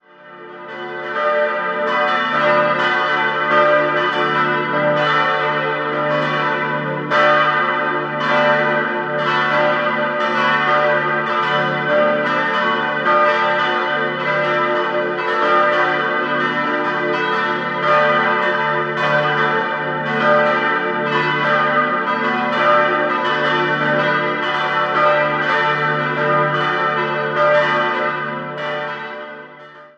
4-stimmiges Salve-Regina-Geläute: d'-fis'-a'-h'
Die drei kleineren Glocken (Martin, Michael und Maria geweiht) wurden 1991, die große Ulrichsglocke im Jahr 2001 von der Gießerei Bachert gegossen